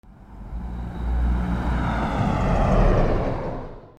13 車の停車音 02
/ E｜乗り物 / E-10 ｜自動車